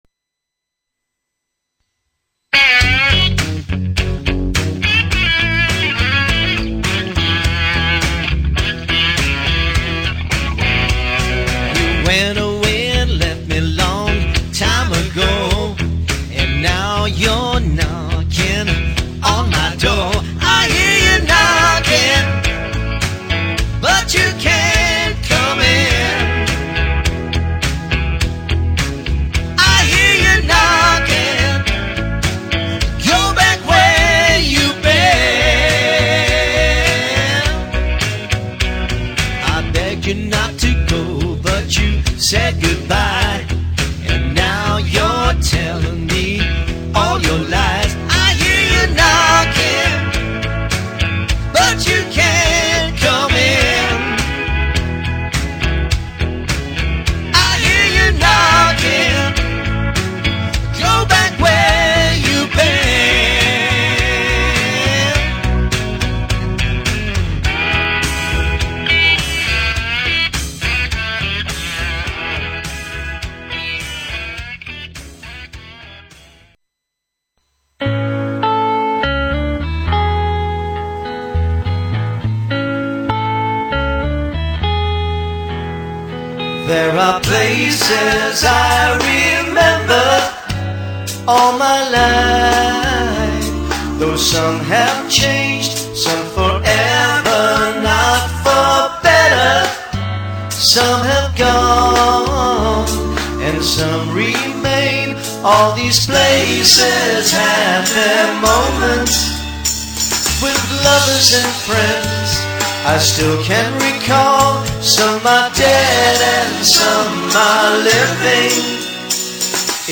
guitar
keyboards
feel good band